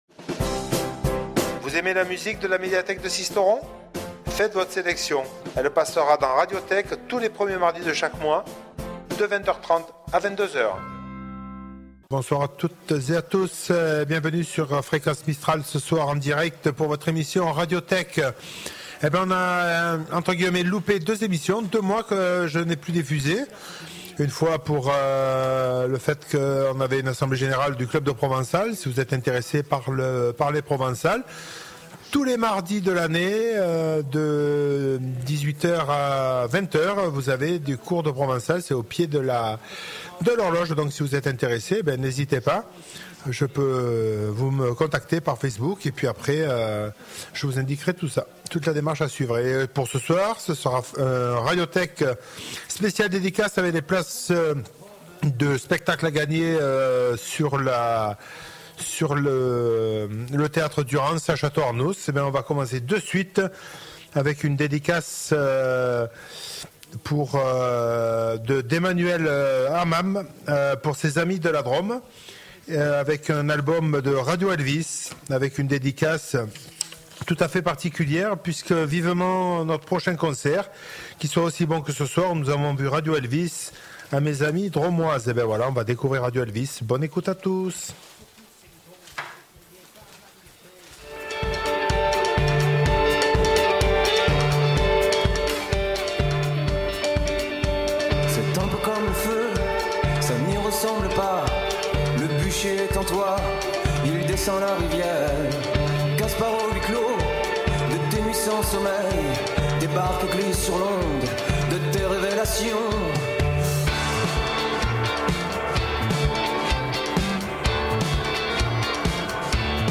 Le rendez-vous incontournable tous les premiers mardi du mois sur le 99.2, ça continue en 2018 !